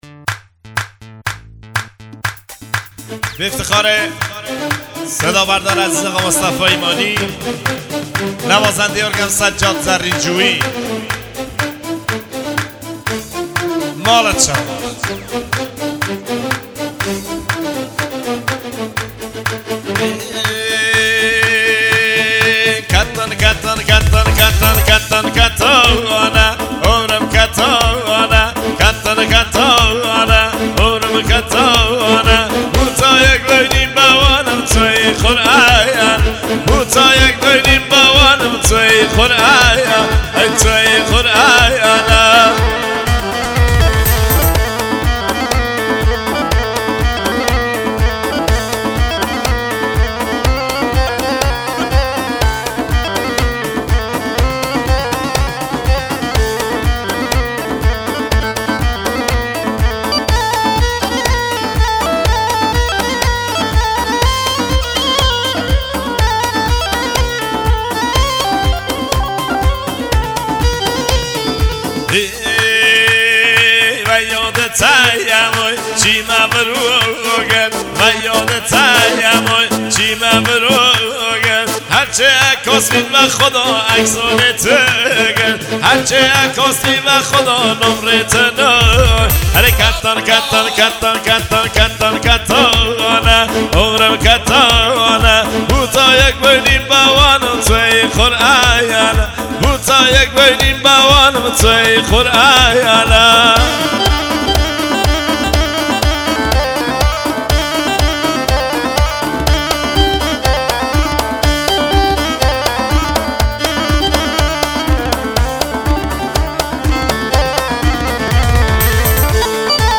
ضبط استودیو تکنیک